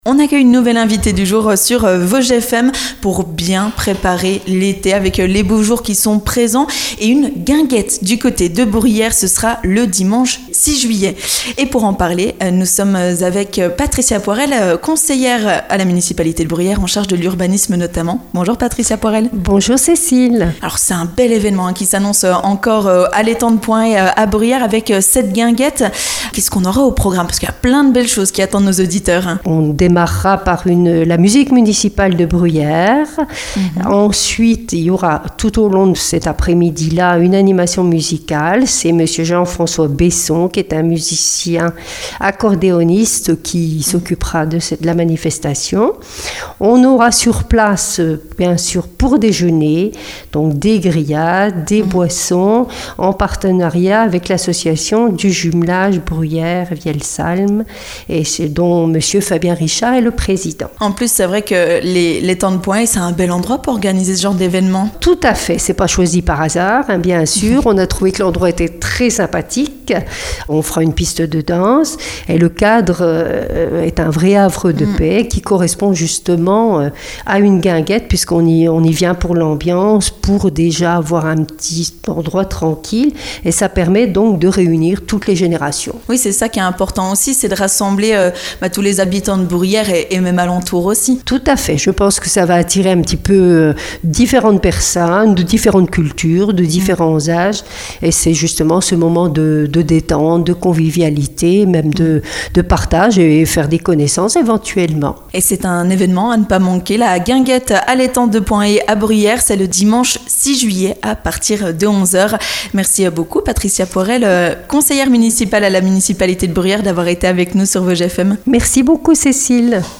On en parle avec Patricia Poirel, conseillère municipale pour la Ville de Bruyères.